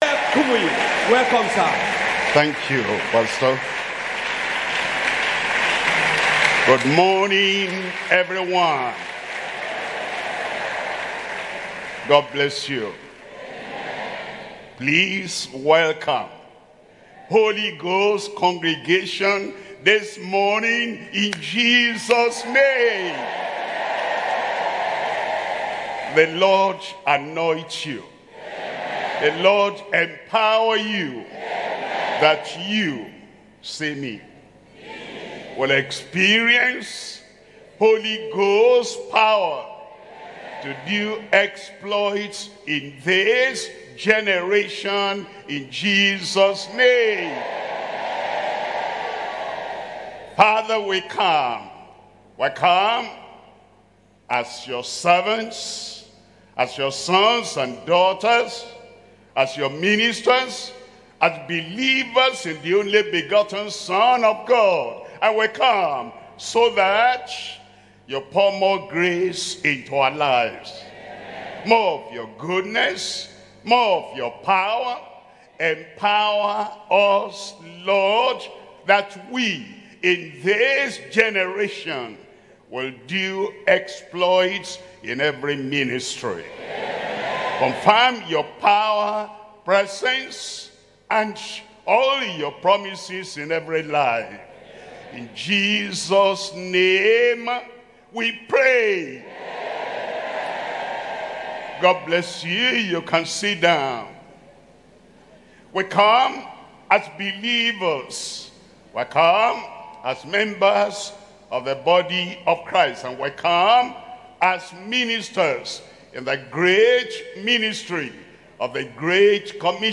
Sermons - Deeper Christian Life Ministry